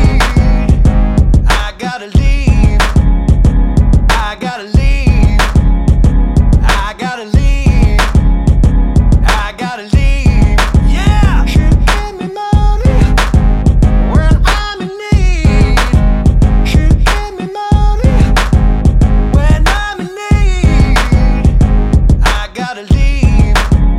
with intro R'n'B / Hip Hop 3:32 Buy £1.50